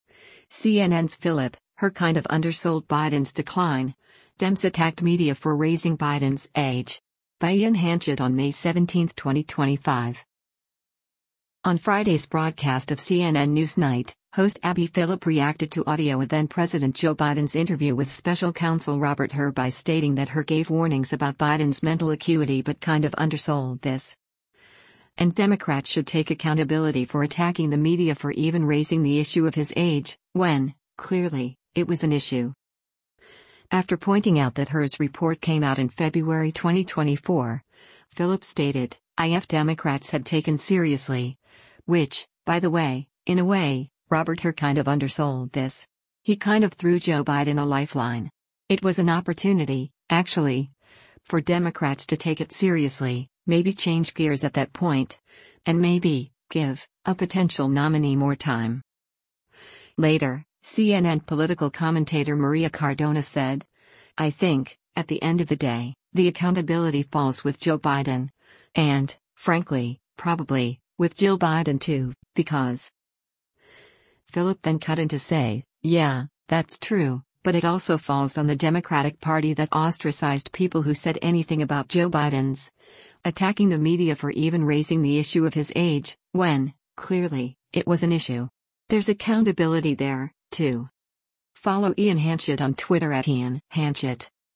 On Friday’s broadcast of “CNN NewsNight,” host Abby Phillip reacted to audio of then-President Joe Biden’s interview with Special Counsel Robert Hur by stating that Hur gave warnings about Biden’s mental acuity but “kind of undersold this.”